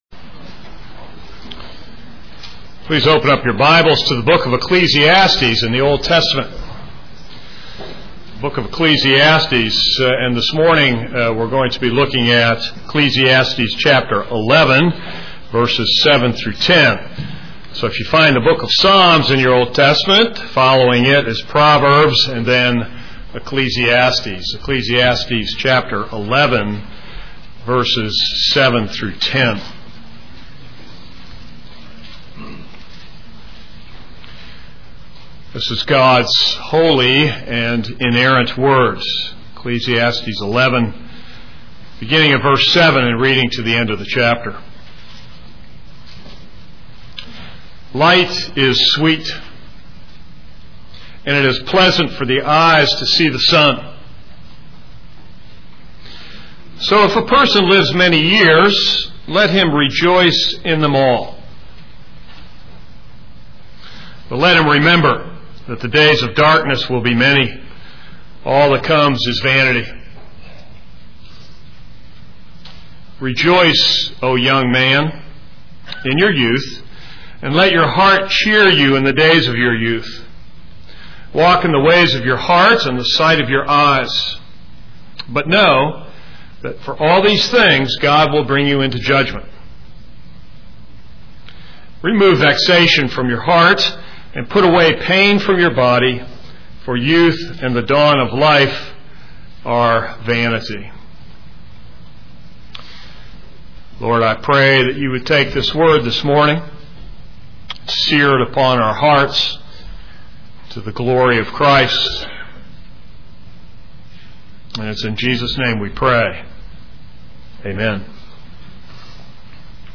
This is a sermon on Ecclesiastes 11:7-10.